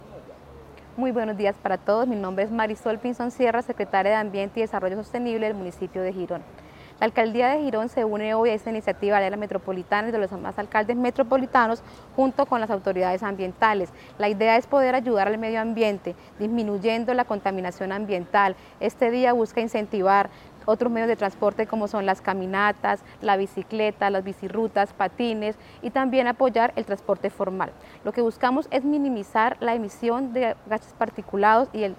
Marisol Pinzón, Secretaria de Ambiente y Desarrollo Sostenible.mp3